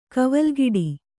♪ kavalgiḍi